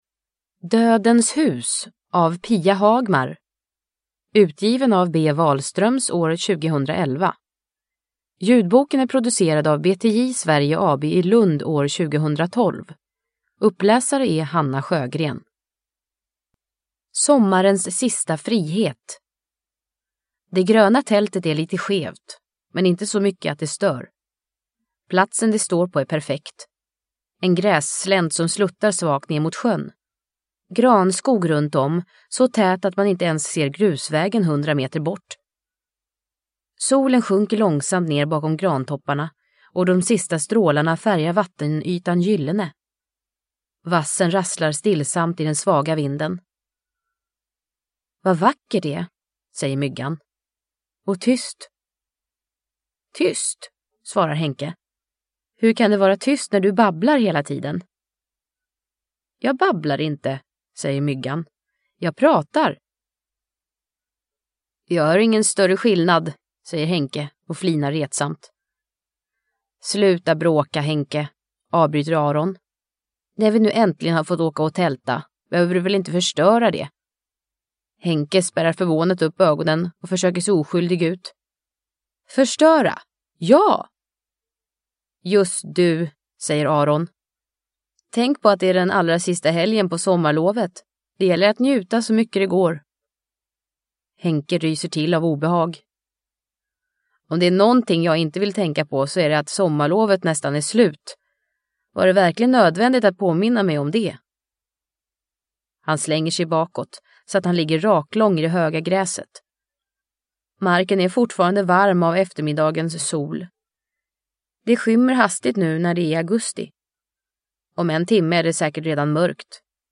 Dödens hus – Ljudbok – Laddas ner